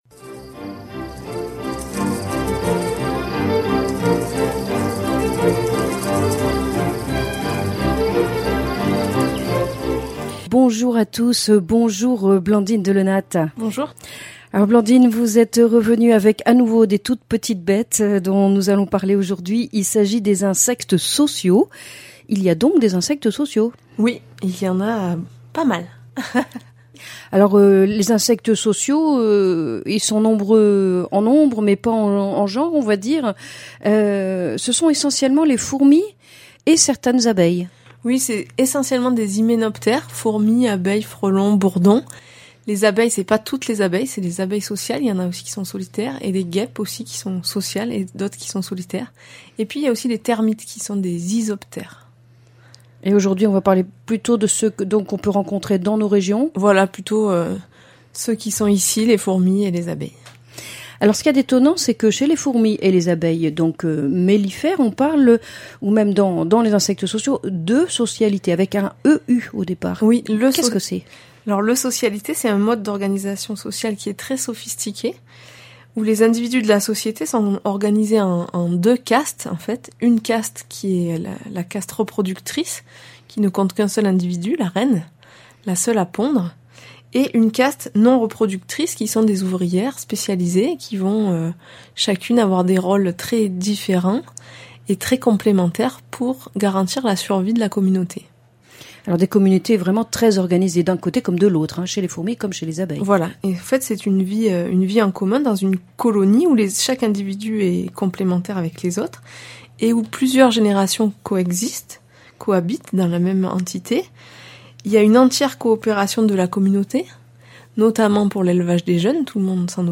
Chronique nature Certains insectes vivent dans un groupe organisé où chaque individu a un rôle précis.